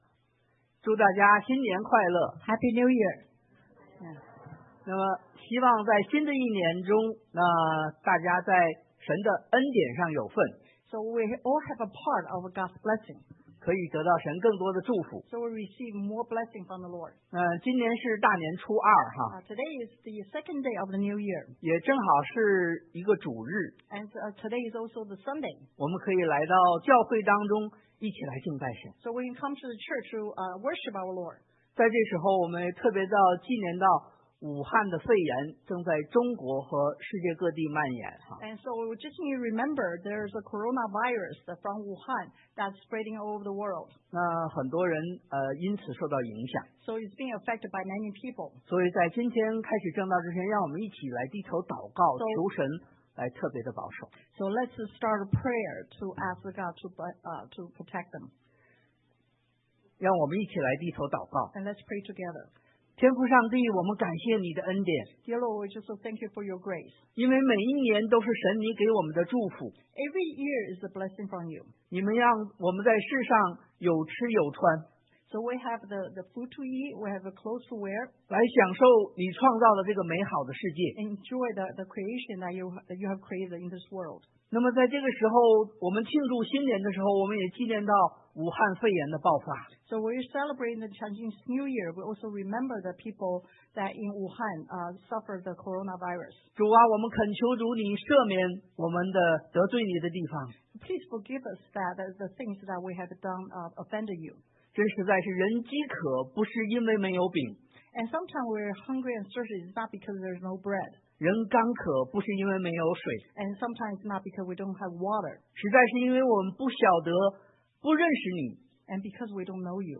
1 Peter 彼前 1:16 Service Type: Sunday AM Bible Text